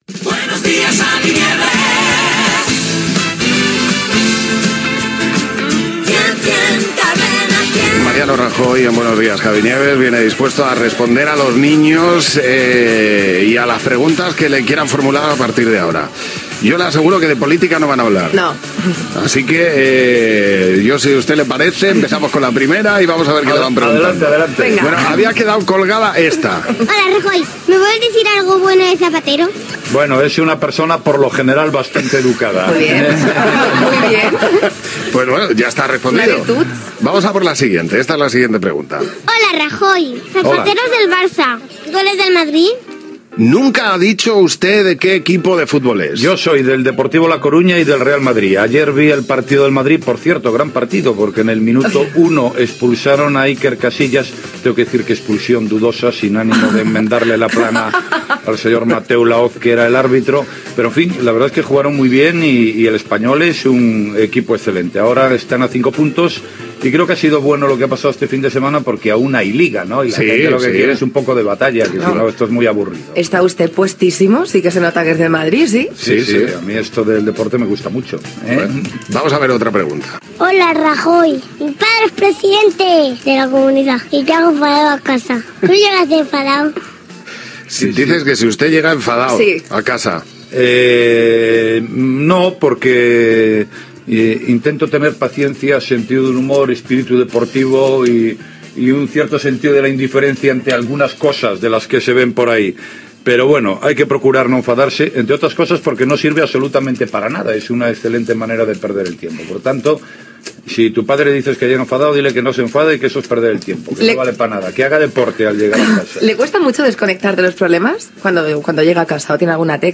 Indicatius del programa i de la cadena, entrevista al president del Partido Popular Mariano Rajoy que contesta les preguntes dels nens i nenes